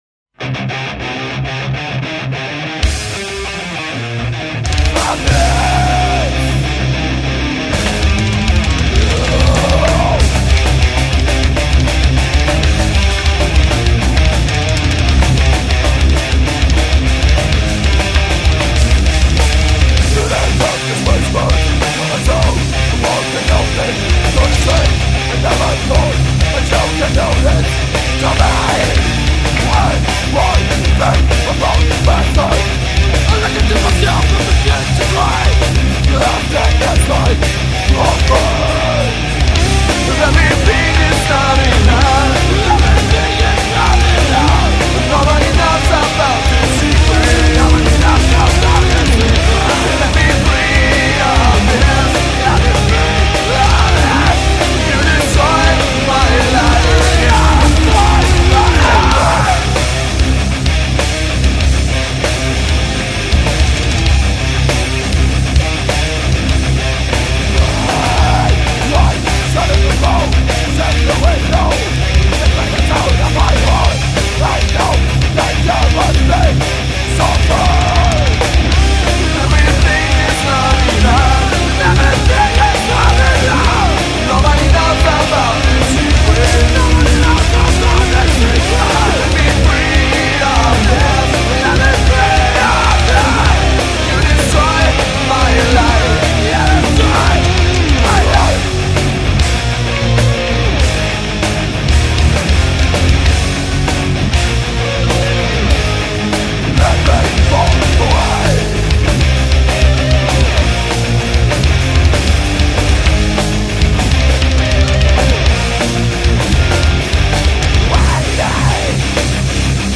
Metal, Metalcore, Thrashcore